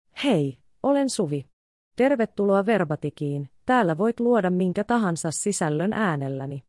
Suvi — Female Finnish AI voice
Suvi is a female AI voice for Finnish.
Voice sample
Listen to Suvi's female Finnish voice.
Female
Suvi delivers clear pronunciation with authentic Finnish intonation, making your content sound professionally produced.